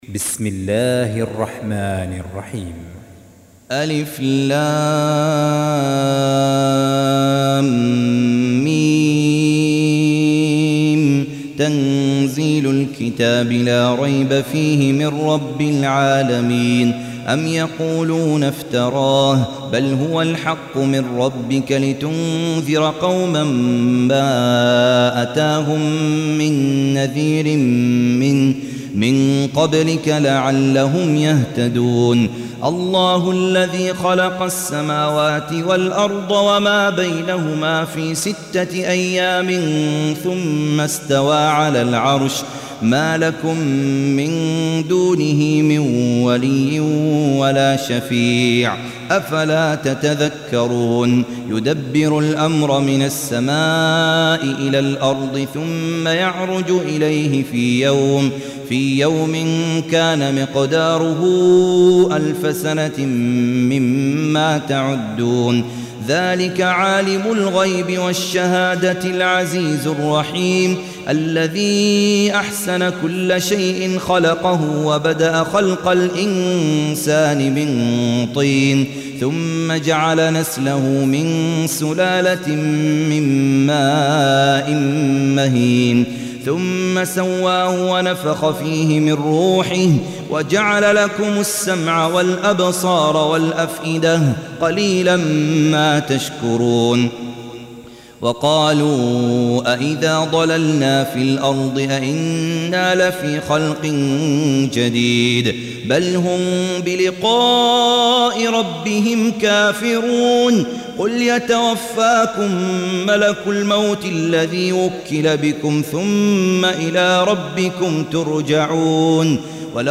Surah Repeating تكرار السورة Download Surah حمّل السورة Reciting Murattalah Audio for 32. Surah As�Sajdah سورة السجدة N.B *Surah Includes Al-Basmalah Reciters Sequents تتابع التلاوات Reciters Repeats تكرار التلاوات